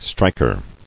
[strik·er]